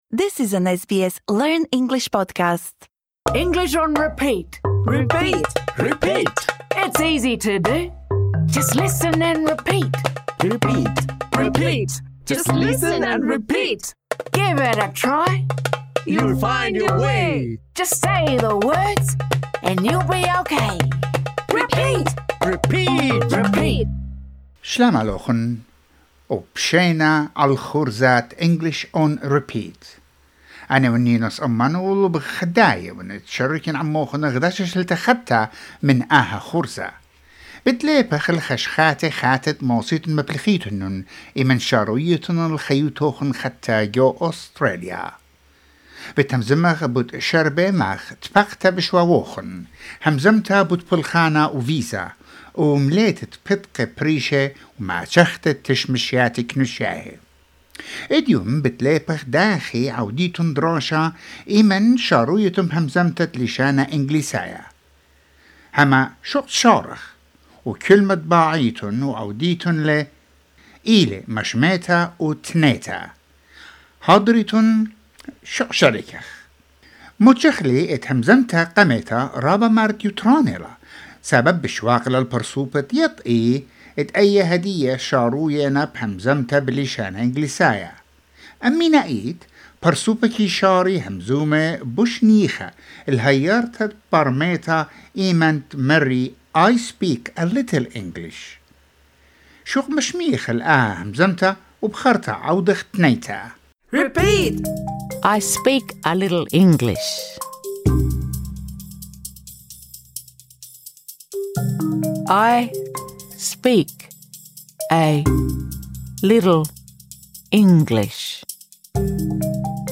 This lesson is designed for easy-level learners. In this episode, we practice saying the following phrases I speak a little English.